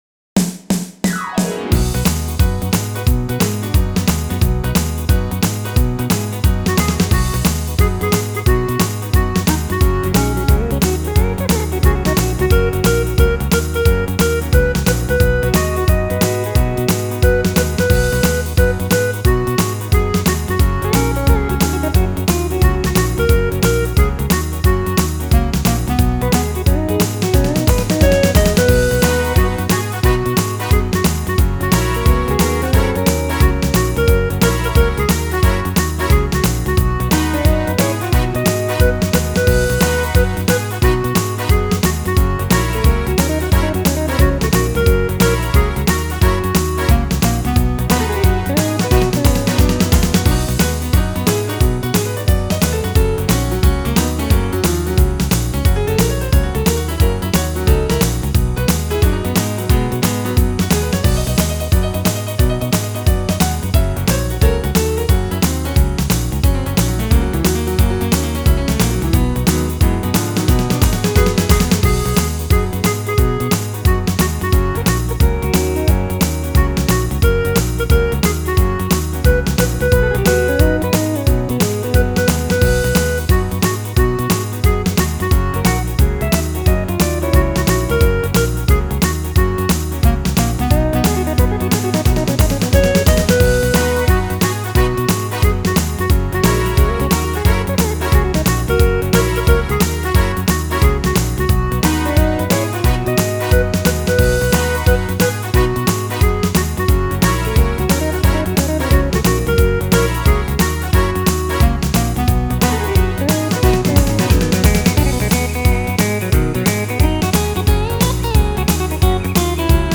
(Country List)